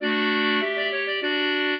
clarinet
minuet0-6.wav